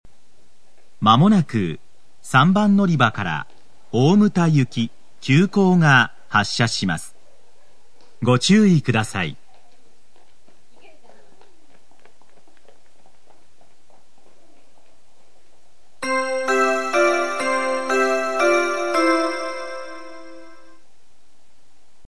＜スピーカー＞　天井埋込型
＜曲名（本サイト概要）＞　西鉄新主要　／　全線-男性放送
○発車放送+サイン（急行・大牟田）